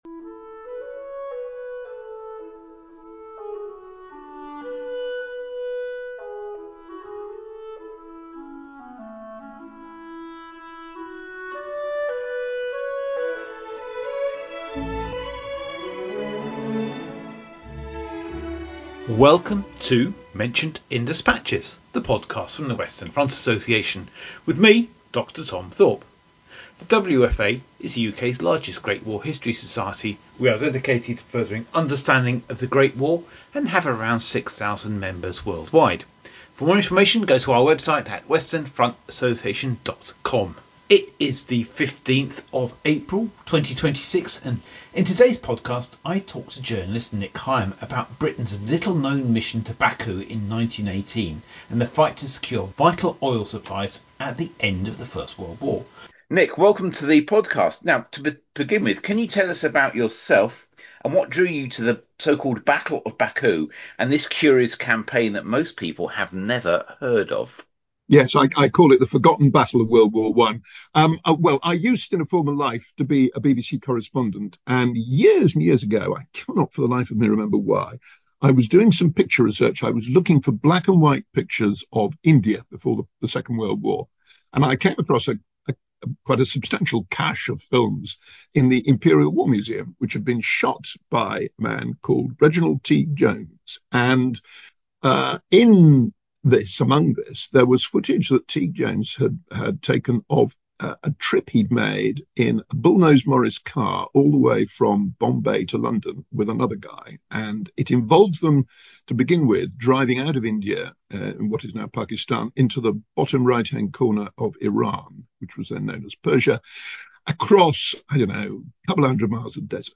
In this episode, Nick Higham explores Britain’s extraordinary and often overlooked campaign in the Caucasus during the closing stages of the First World War. Drawing on his book Mavericks, he discusses the diverse group of soldiers, diplomats and intelligence officers sent to Baku to prevent Ottoman and Bolshevik control of critical oil resources. The interview highlights the strategic importance of oil, the chaotic political environment and the personalities involved in the mission.